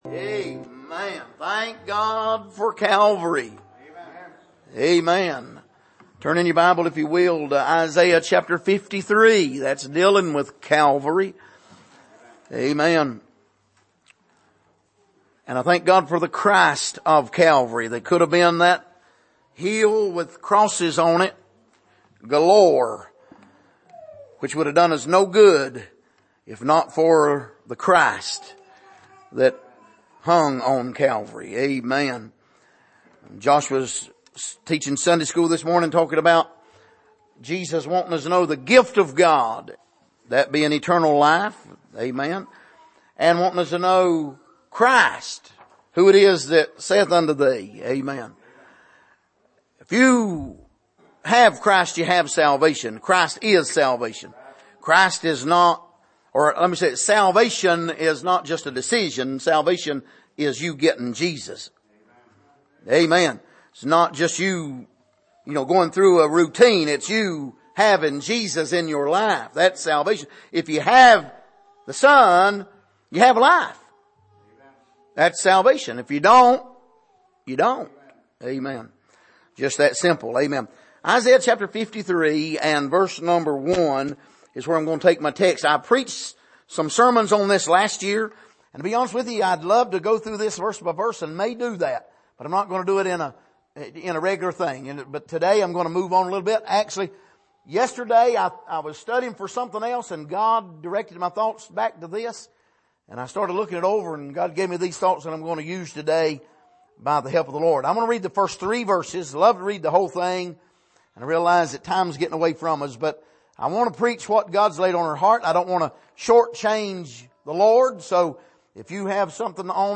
Passage: Isaiah 53:1-3 Service: Sunday Morning